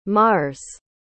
Planeta Tradução em Inglês Pronúncia